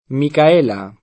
vai all'elenco alfabetico delle voci ingrandisci il carattere 100% rimpicciolisci il carattere stampa invia tramite posta elettronica codividi su Facebook Micaela [sp. mika % la ; italianizz. mika $ la ] pers. f. (= Michela)